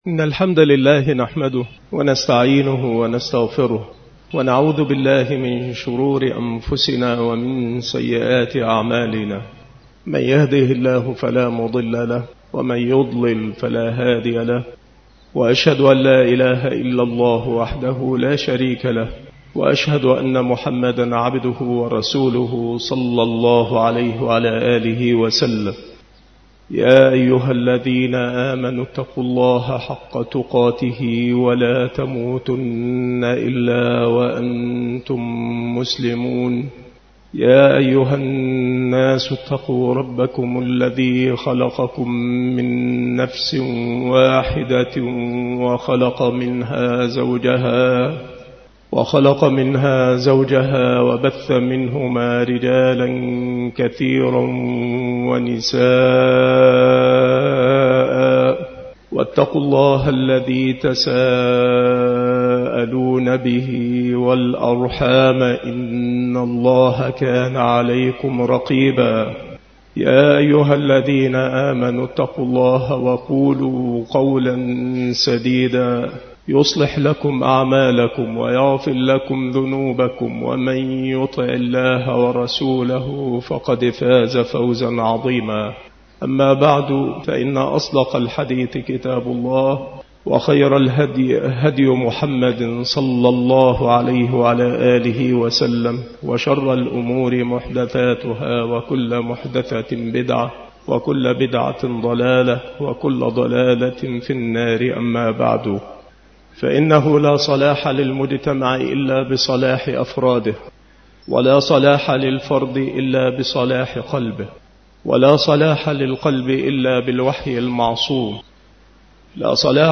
مكان إلقاء هذه المحاضرة بالمسجد الشرقي - سبك الأحد - أشمون - محافظة المنوفية - مصر نبذة مختصرة عن المحاضرة